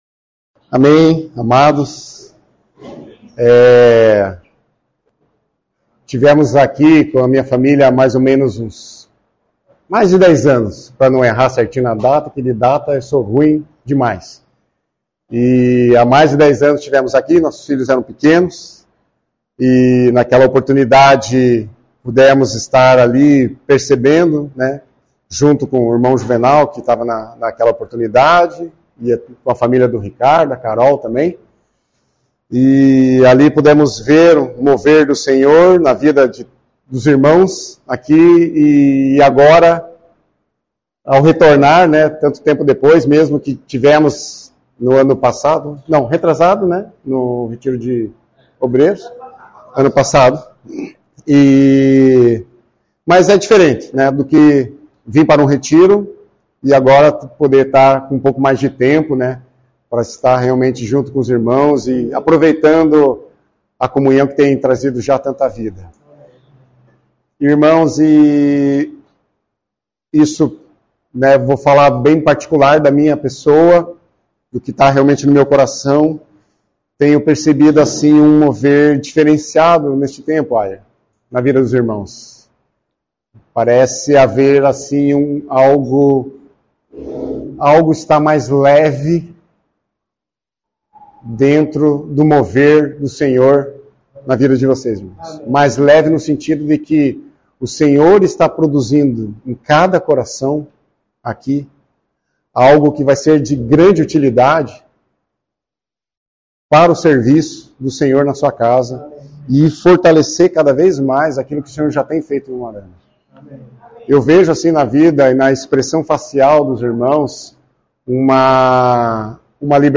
Mensagem compartilhada
na reunião da igreja em Umuarama